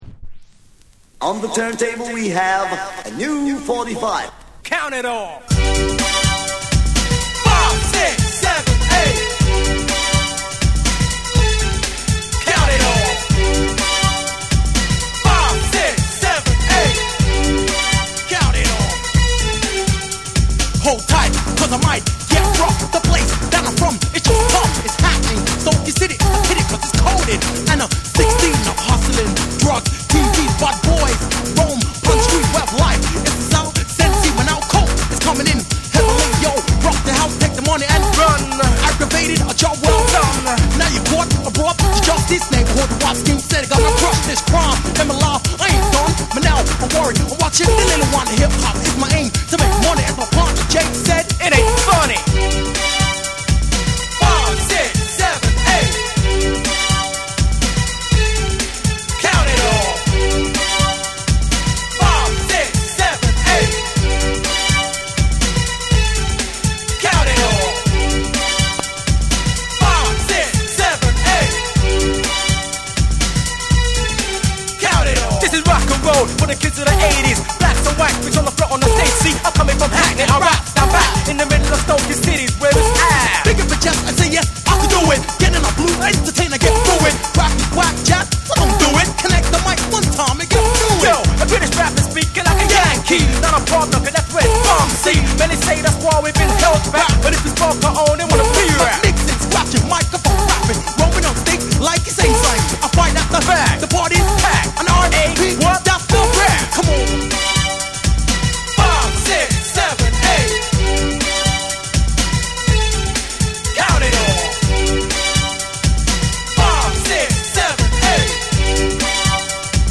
レイブにも通ずる要素を含むヒップハウスのトラックに、煽りを効かせた男性MCがフロウする
BREAKBEATS / TECHNO & HOUSE